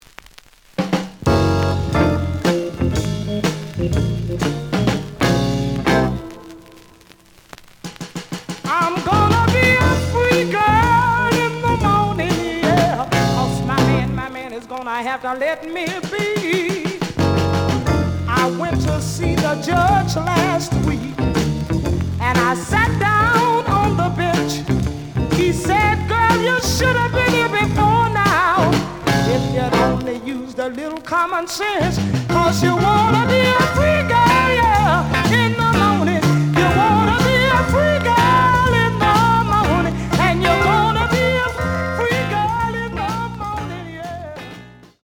試聴は実際のレコードから録音しています。
●Genre: Rhythm And Blues / Rock 'n' Roll